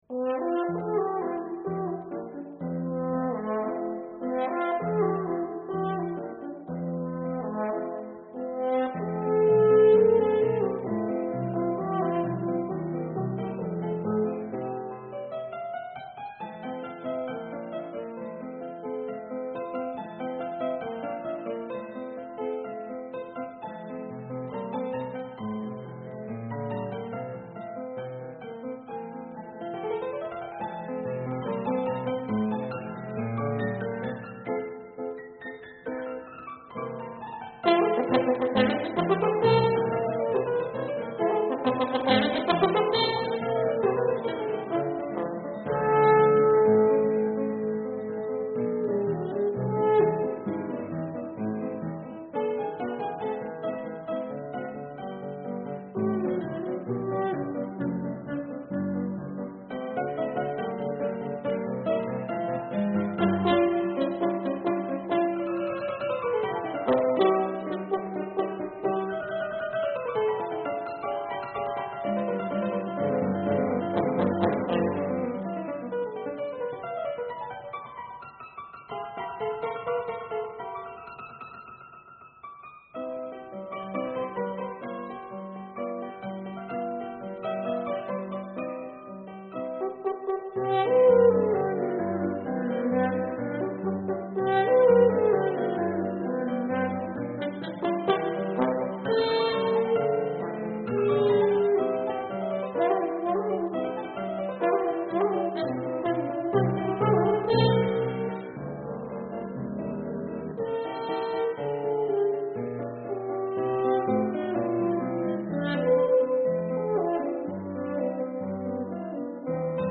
Horn
Fortepiano
(Period Instruments)